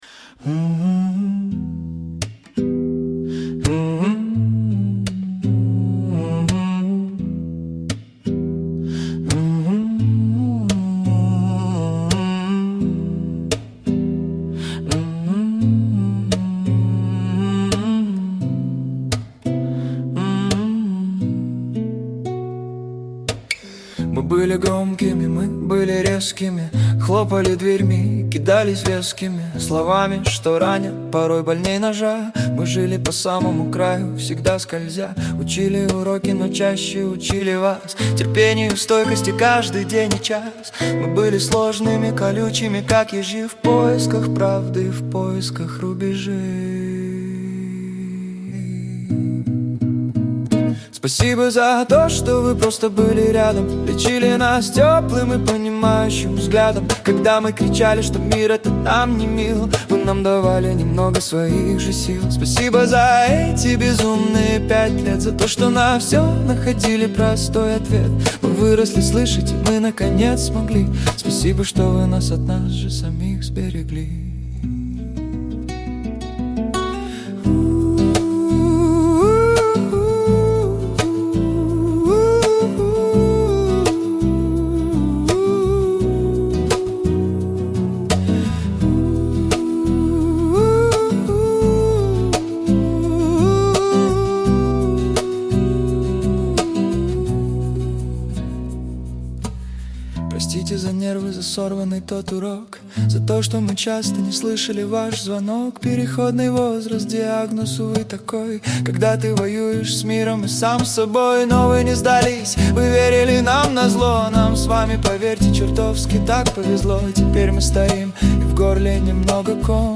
это акустическая баллада в стиле русского рока
Темп 85 BPM: Спокойный ритм сердца.
🎸 Слушать пример (Acoustic, 85 BPM):
🎵 Стиль: Acoustic Rock / Indie Folk Скачать MP3